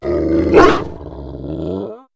mabosstiff_ambient.ogg